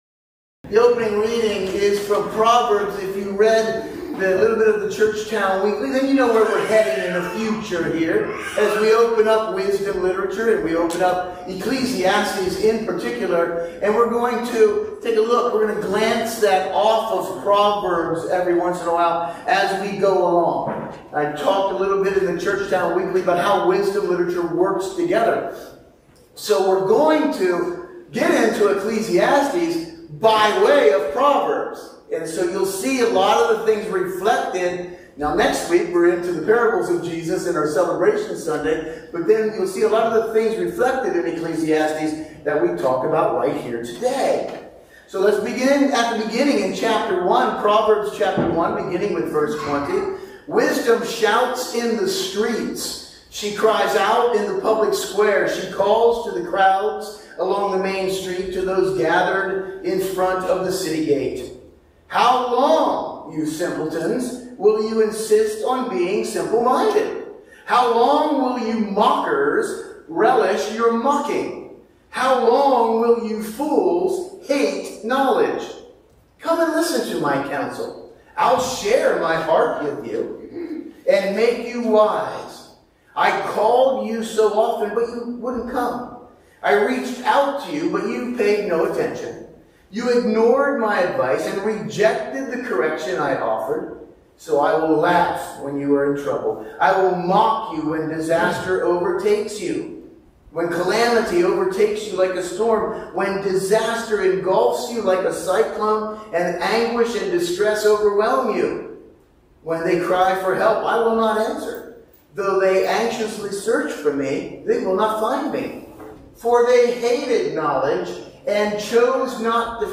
Sunday Morning Service – June 23, 2024